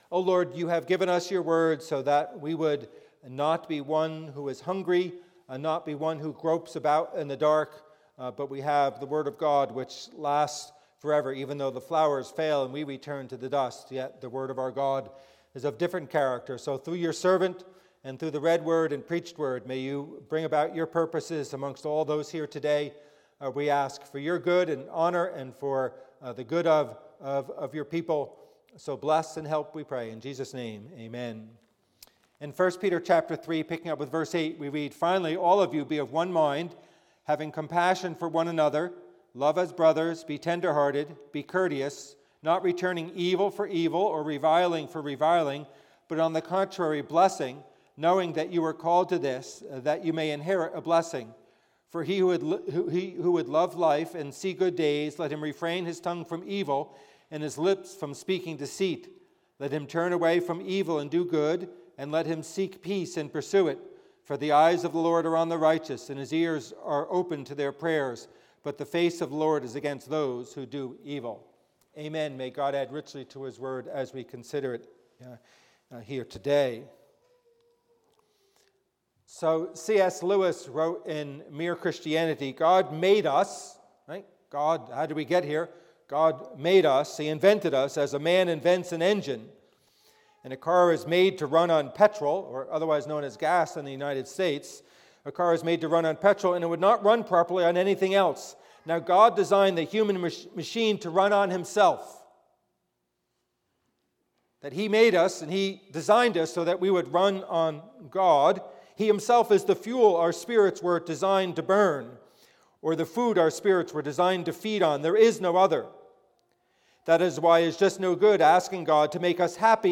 Passage: 1 Peter 3:8-12 Service Type: Worship Service